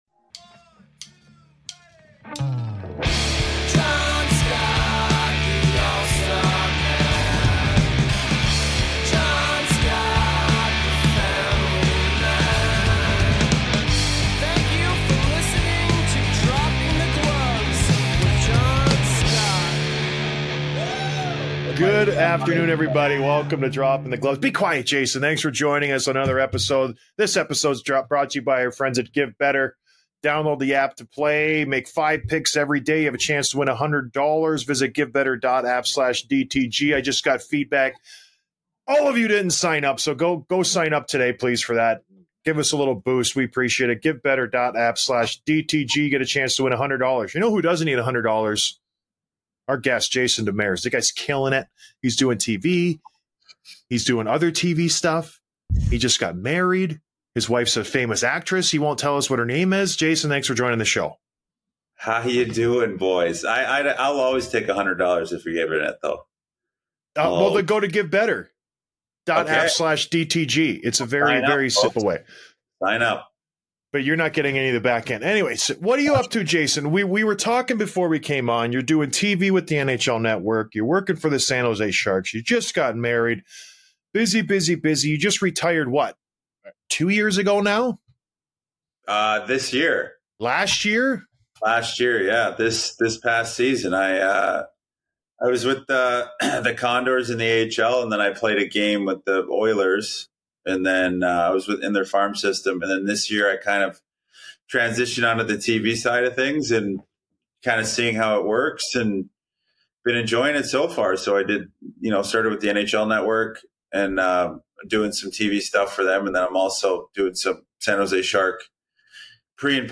Interview with Jason Demers